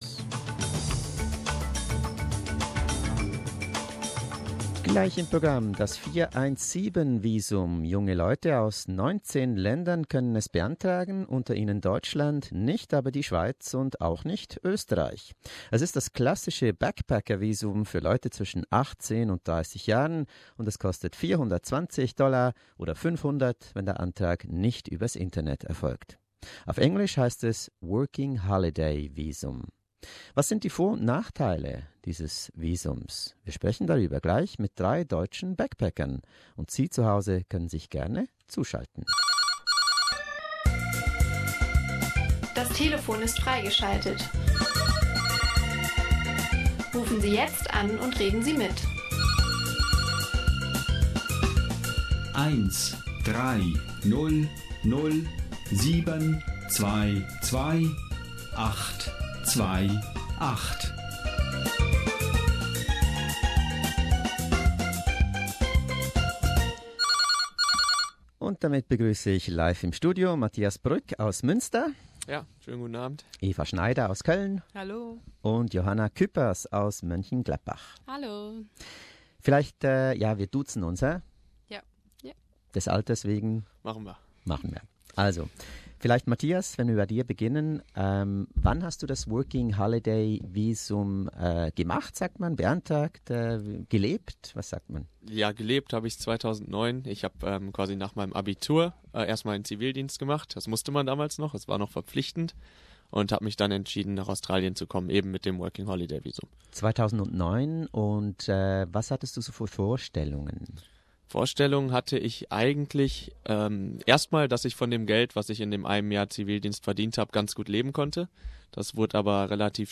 Doch was sind die Vor- und Nachteile des Visums und des Backpacker-Lebens ganz allgemein? Drei junge Deutsche erzählen im SBS-Talk.